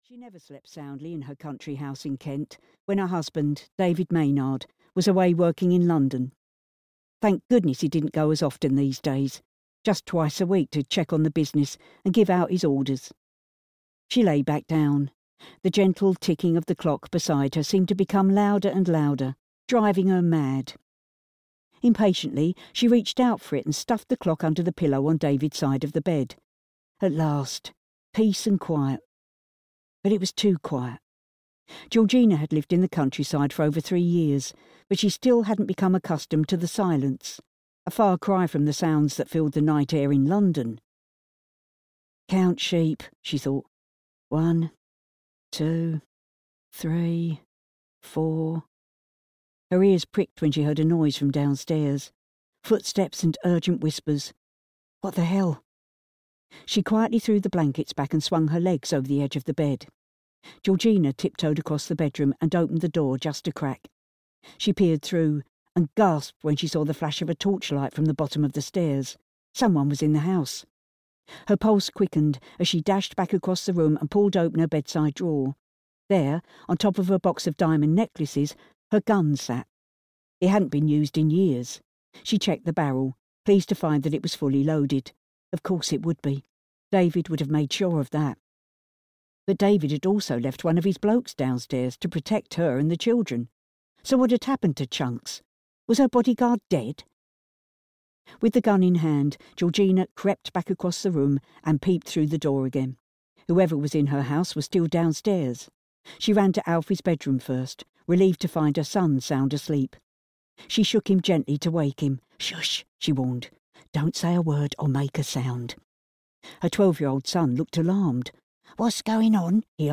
Raven (EN) audiokniha
Ukázka z knihy